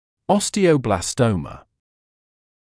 [ˌɔstɪə(u)ˌblɑːs’təumə][ˌостио(у)ˌблаːс’тоумэ]остеобластома